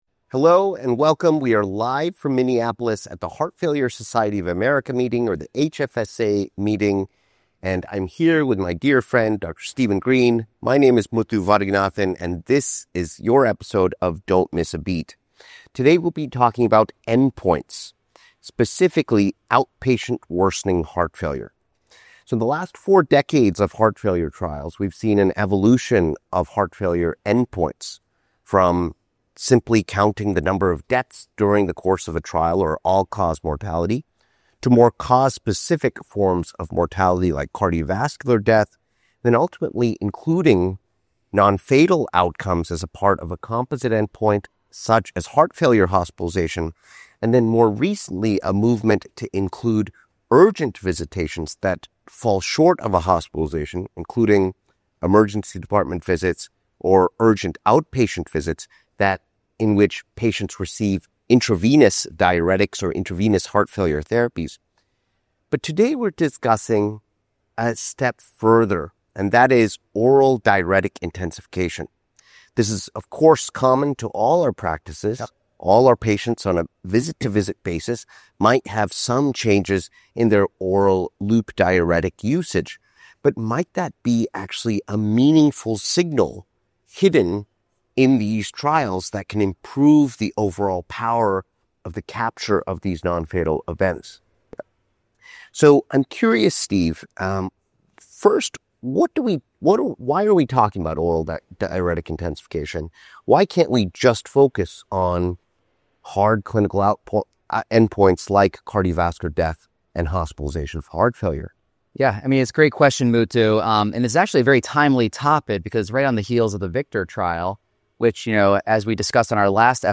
Recorded live at HFSA 2025, hosts explore the evolving landscape of heart failure endpoints, focusing on the significance of oral diuretic intensification in outpatient care.